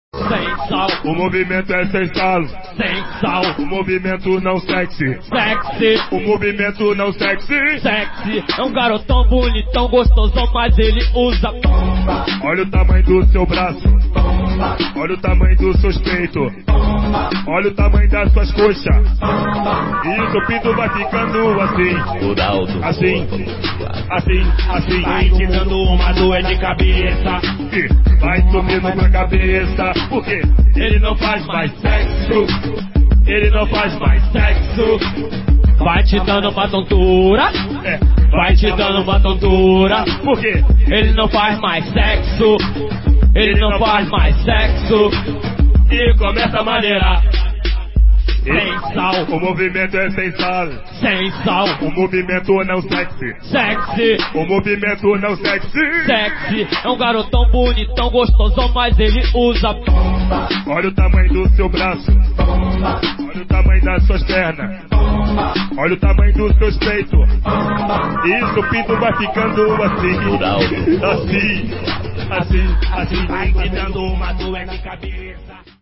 Engraçadas, Música, Musculação Ele usa bomba É um garotão, bonitão, gostosão mas ele usa bomba!